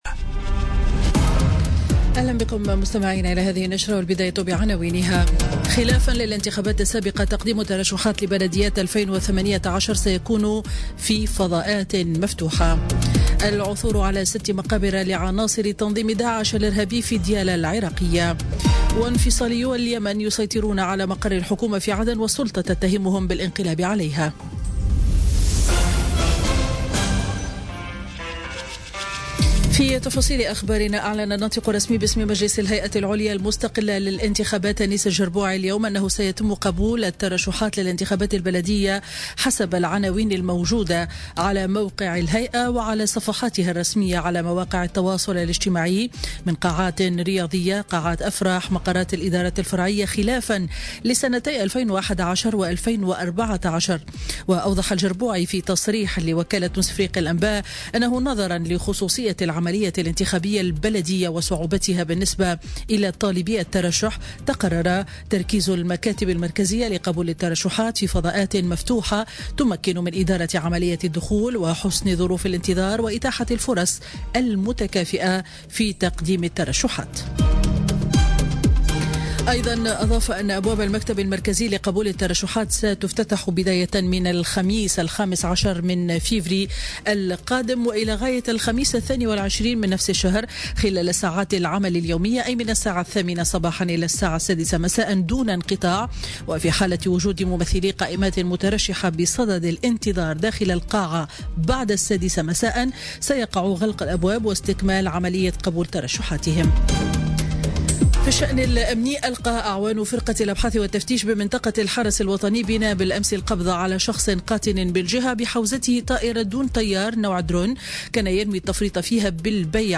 نشرة أخبار السابعة صباحا ليوم الأحد 28 جانفي 2018